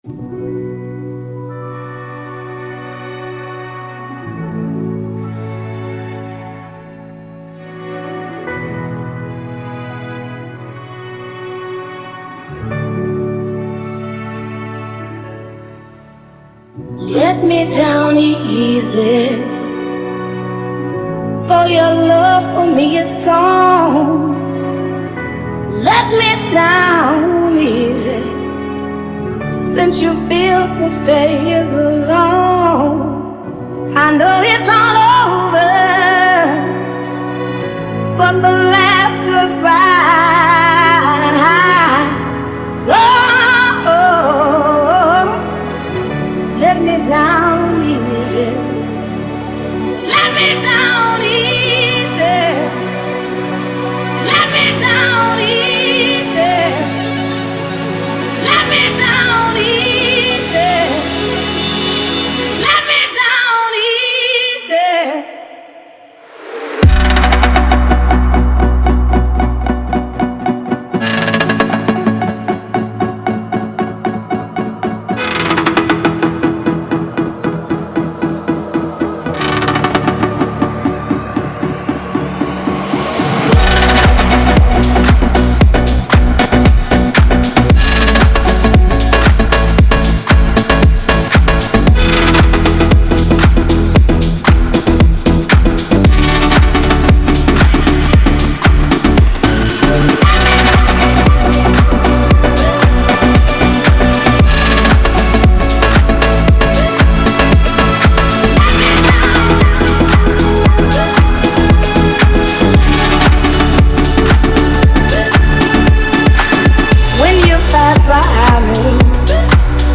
• Жанр: House